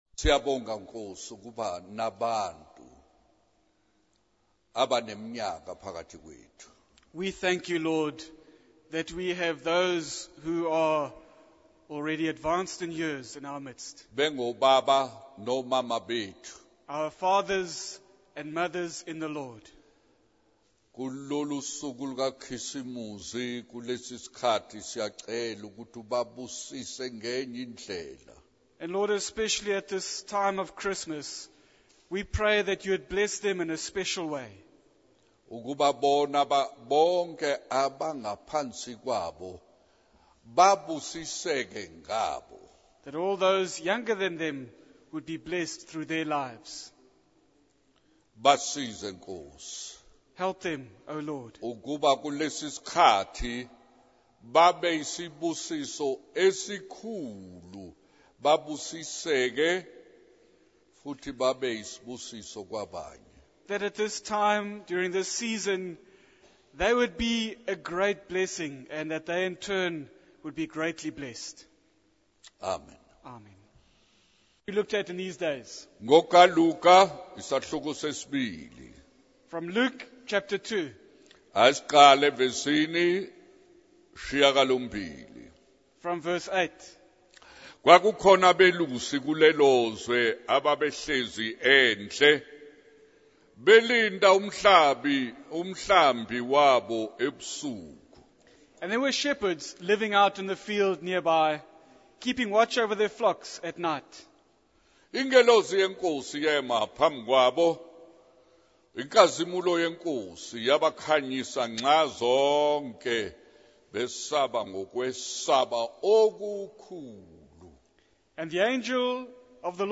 He encourages listeners to spend time alone with God and warns against the temptations and sins of the world. The sermon concludes with the reminder that in order to preach effectively, one must first receive a message from God.